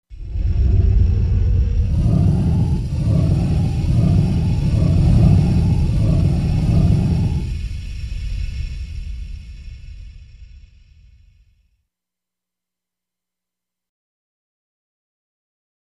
Furnace | Sneak On The Lot
Gas Furnace Ignition, Close Up To Igniter